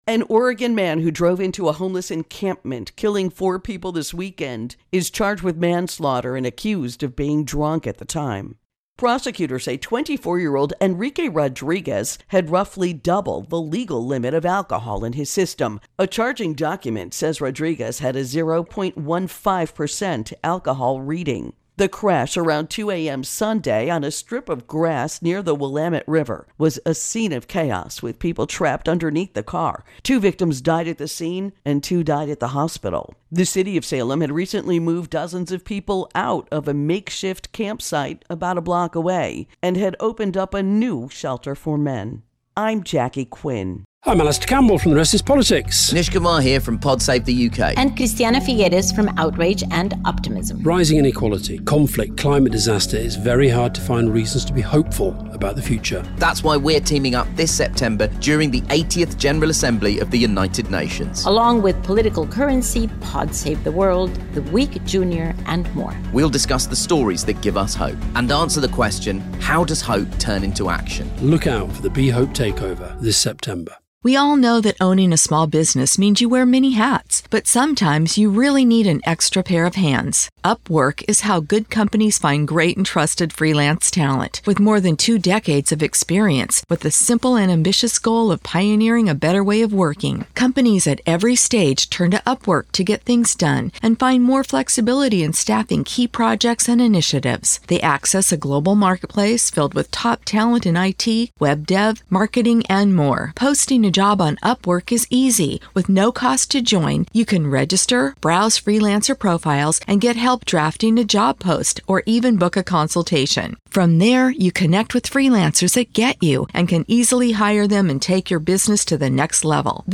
Homeless Encampment Fatal Crash Intro and Voicer